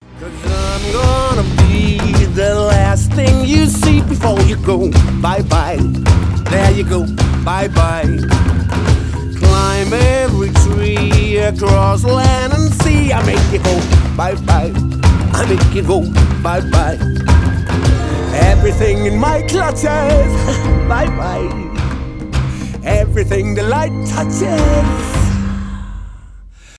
из мультфильмов , поп